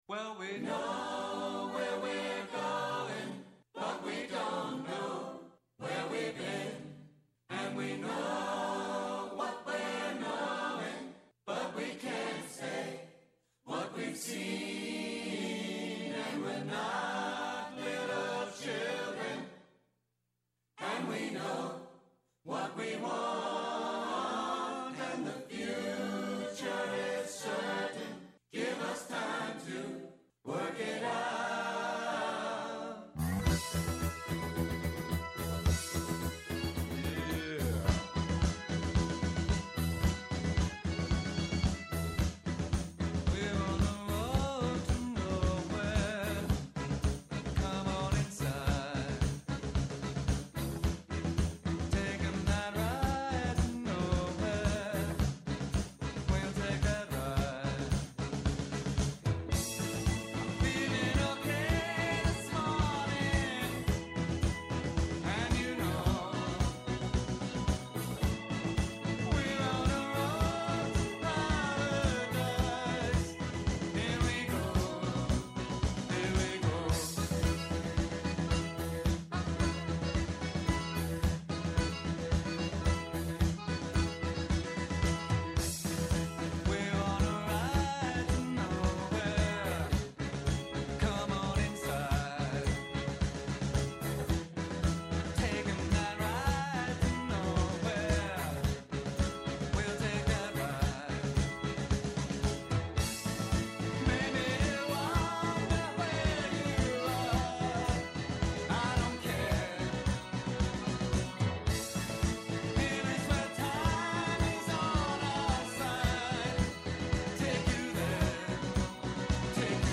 Συμφωνούν, διαφωνούν, φωτίζουν και αποκρυπτογραφούν τα γεγονότα με πολύ κέφι, πολλή και καλή μουσική και πολλές εκπλήξεις. Με ζωντανά ρεπορτάζ από όλη την Ελλάδα, με συνεντεύξεις με τους πρωταγωνιστές της επικαιρότητας, με ειδήσεις από το παρασκήνιο, πιάνουν τιμόνι στην πρώτη γραμμή της επικαιρότητας.